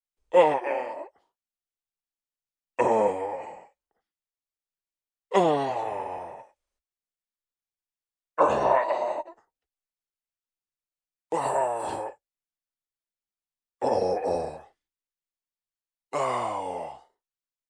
Le bajo algo más de cuatro semitonos con Waves SoundShifter. Después reduzco la duración de cada grito time-stretching con la herramienta TCE de Pro Tools. Ya se parece más a un orco y menos a una escena erótica:
Orco-octavado.mp3